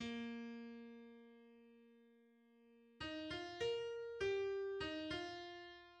Тональность ми-бемоль мажор
симфонический оркестр
Симфония написана для 2 флейт, 2 гобоев, 2 кларнетов, 2 фаготов, 4 валторн, 3 труб, 3 тромбонов, литавр и струнных.
• 1. Tempo molto moderato – Allegro moderato (ma poco a poco stretto) – Vivace molto – Presto – Più presto (ми-бемоль мажор).